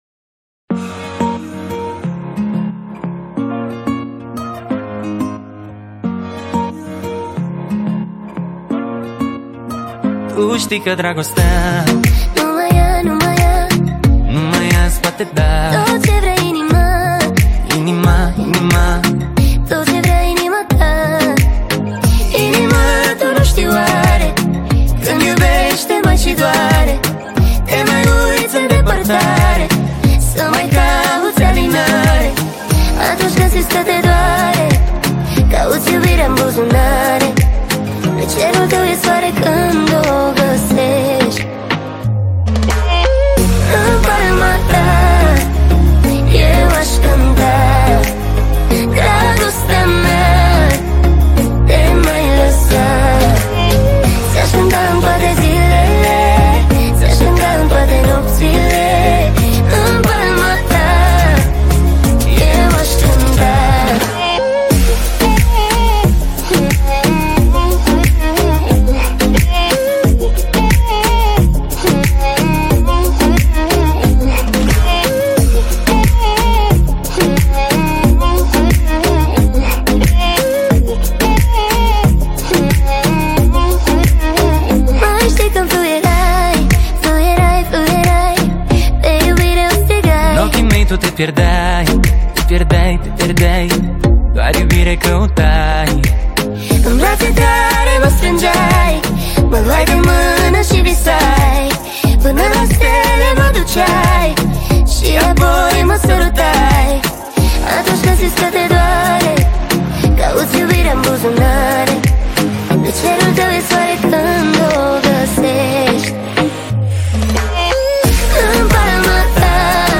Muzica Usoara